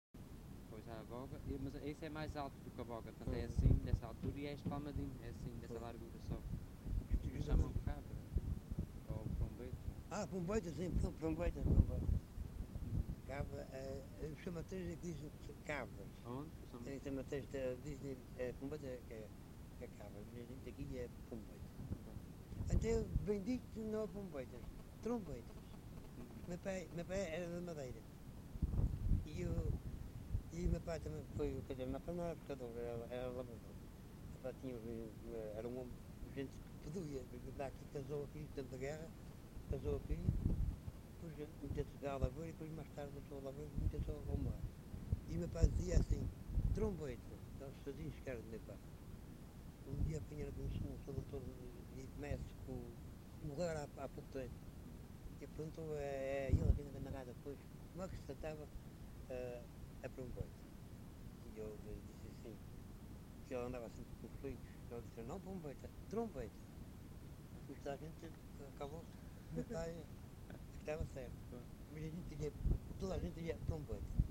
LocalidadePraia da Vitória (Praia da Vitória, Angra do Heroísmo)